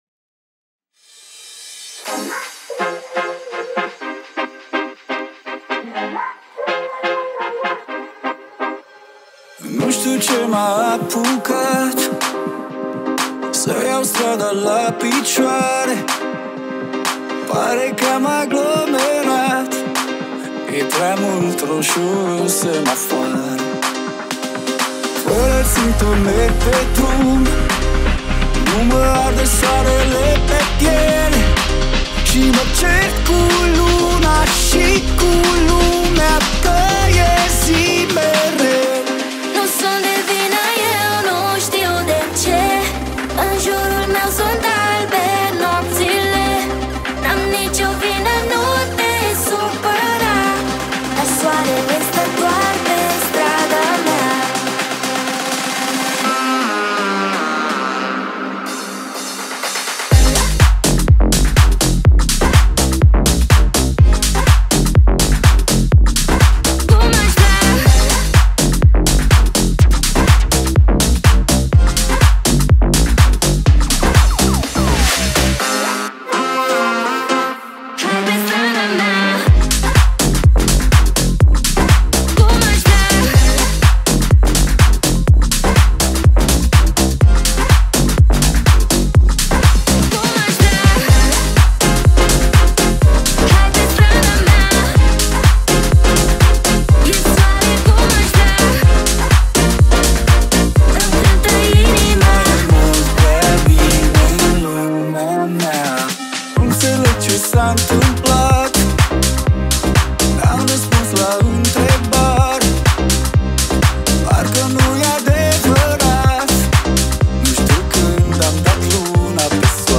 • Жанр: Pop, Electronic, Dance